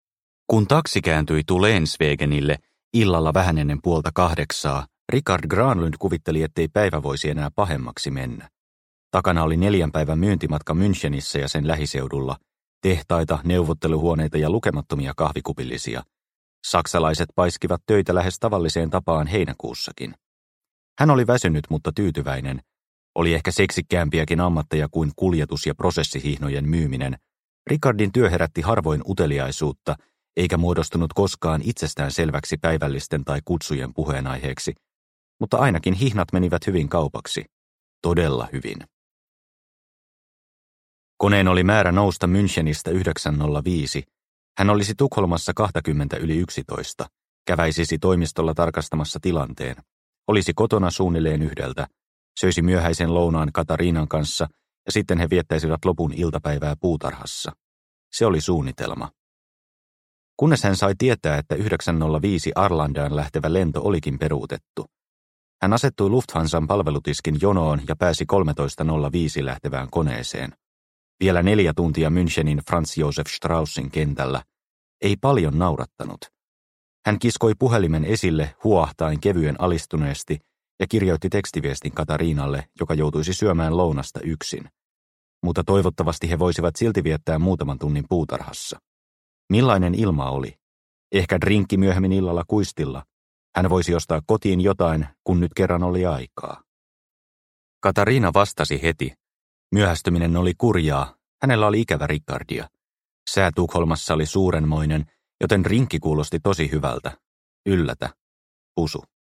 Oppipoika – Ljudbok – Laddas ner